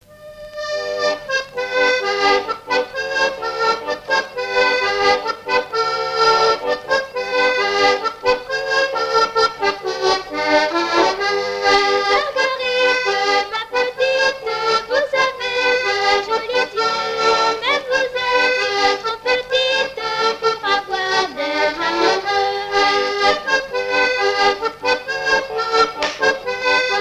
Chants brefs - A danser
danse : mazurka
Pièce musicale inédite